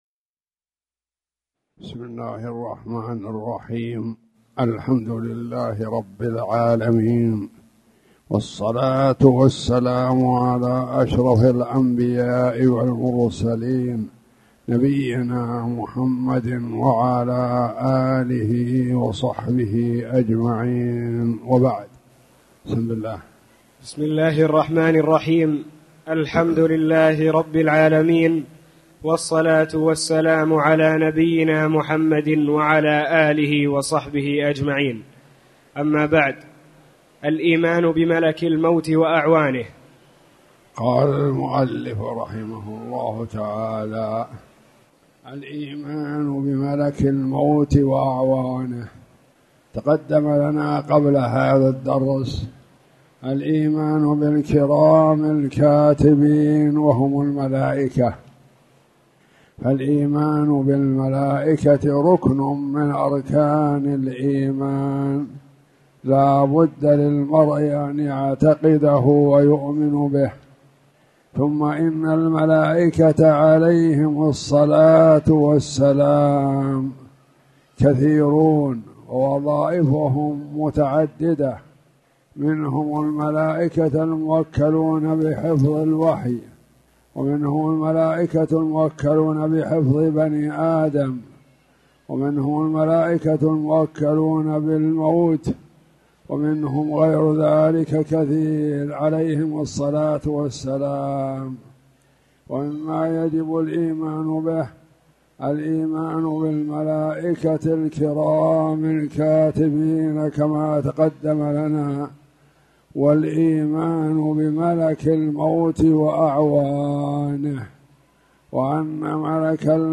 تاريخ النشر ٢ ذو القعدة ١٤٣٨ هـ المكان: المسجد الحرام الشيخ